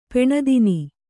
♪ peṇadini